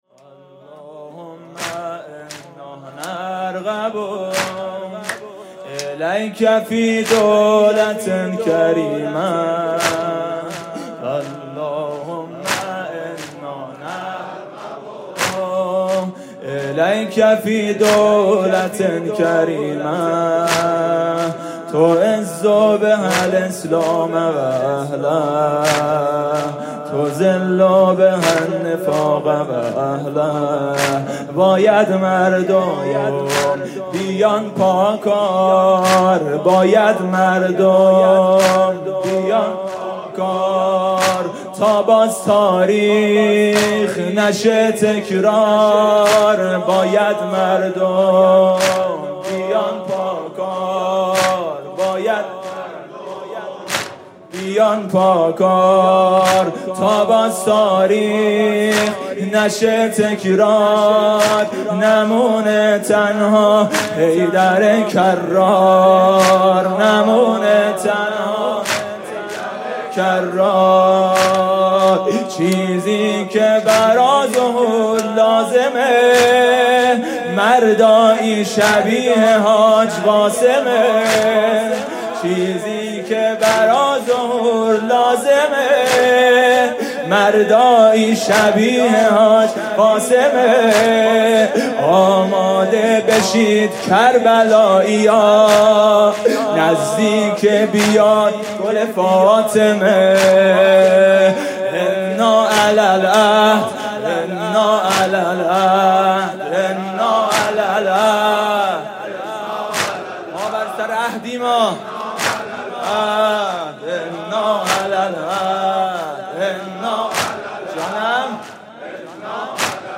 شب پنجم فاطمیه اول ۱۴۰۴ | هیأت میثاق با شهدا
music-icon رجز